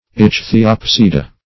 Search Result for " ichthyopsida" : The Collaborative International Dictionary of English v.0.48: Ichthyopsida \Ich`thy*op"si*da\, n. pl.